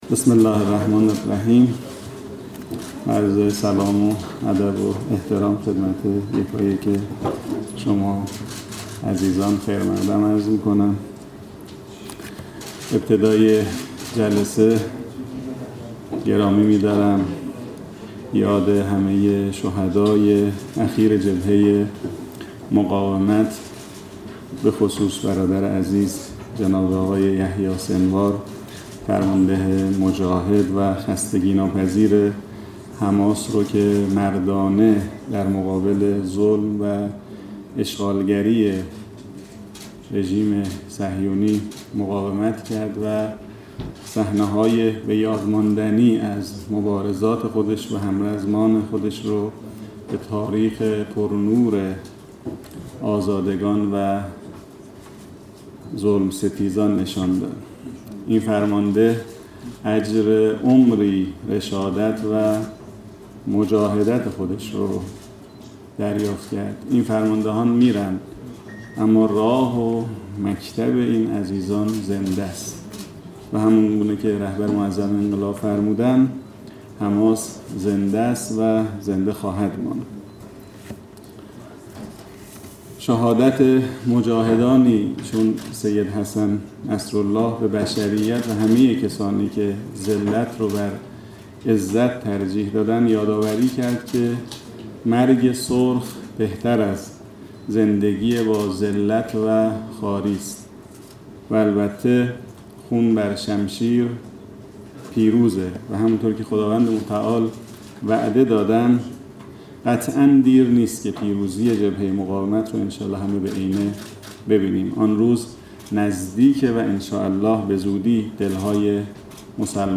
هادی طحان نظیف امروز شنبه در نشست خبری با بیان اینکه سال آینده انتخابات شوراهای شهر و روستا برگزار خواهد شد، گفت: انتخابات میان دوره‌ای مجلس خبرگان رهبری و میان دوره‌ای مجلس شورای اسلامی همزمان با انتخابات شوراها برگزار می‌شود.